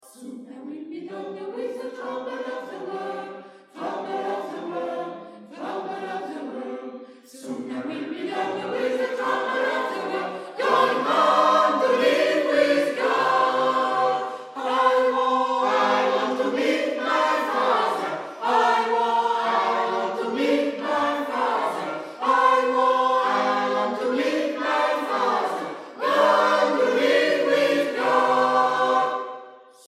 Répertoire chorale 2020-2021